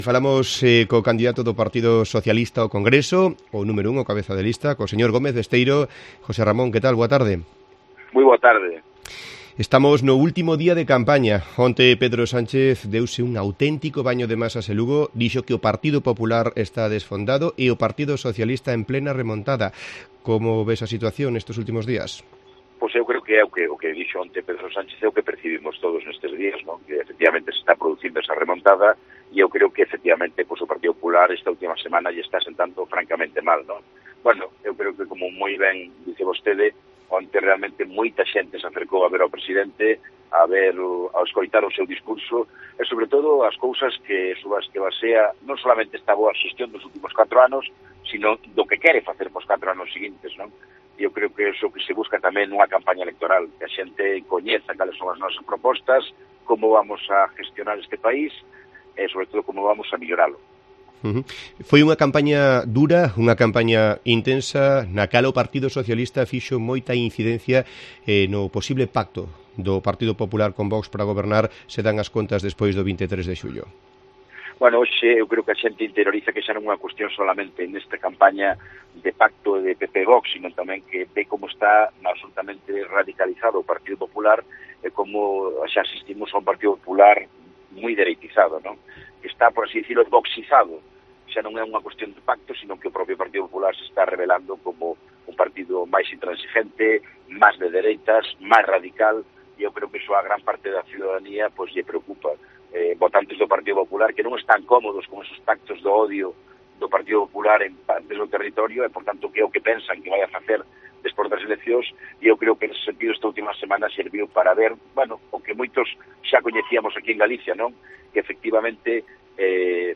Entrevista Gómez Besteiro